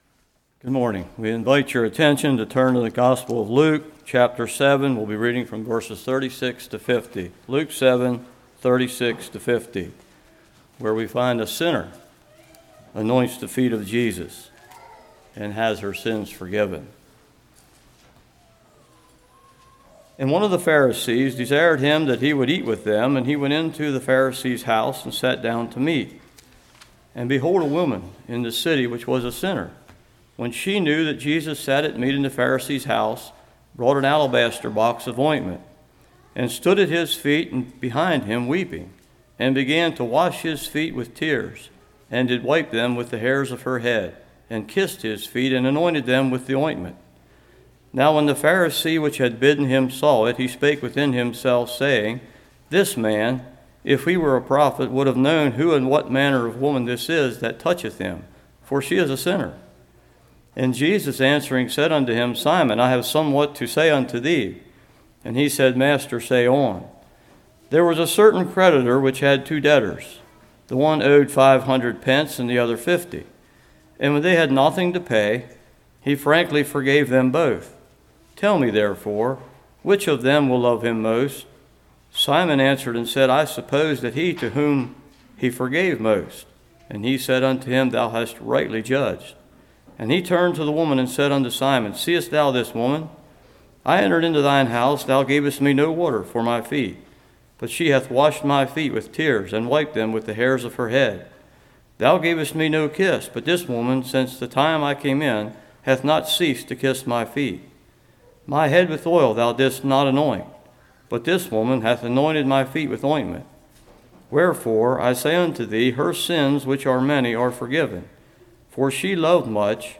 Luke 7:36-50 Service Type: Morning Weeping Perfume God’s Rebuke Simon’s Debt « Hymn Sing Abraham